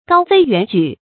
高飛遠舉 注音： ㄍㄠ ㄈㄟ ㄧㄨㄢˇ ㄐㄨˇ 讀音讀法： 意思解釋： 舉：飛、去。飛得又高又遠。比喻前程廣大。